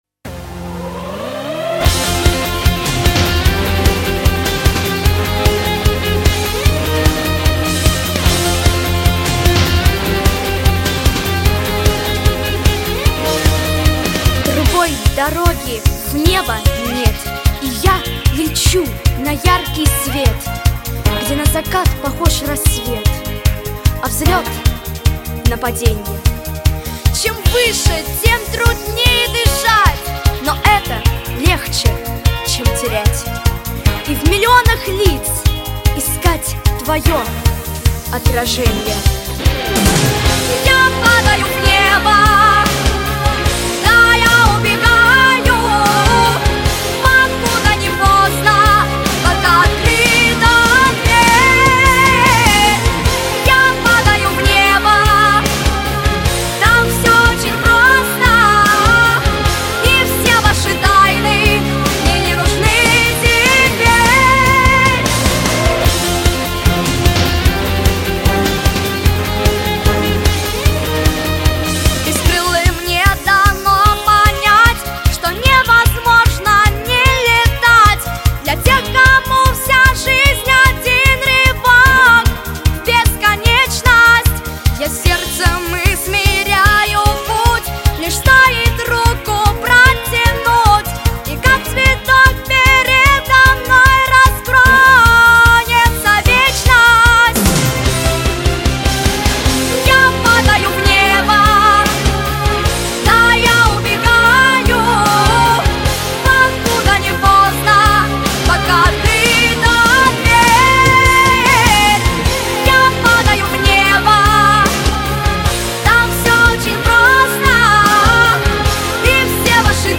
• Категория: Детские песни
подростковые песни